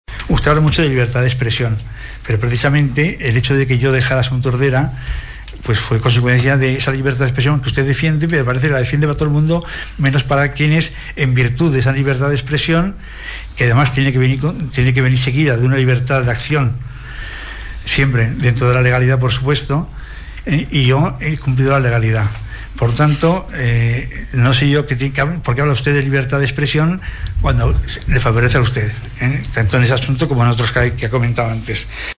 El ple de l’Ajuntament va debatre ahir una moció presentada pel grup municipal de Som Tordera-Entesa en defensa dels drets polítics i civils de les persones, la democràcia i la llibertat d’expressió i en contra de les conductes que vulnerin aquests principis fonamentals.
El regidor no adscrit, José Carlos Villaro va utilitzar l’argument de llibertat d’expressió, per justificar la seva baixa del grup de Som Tordera.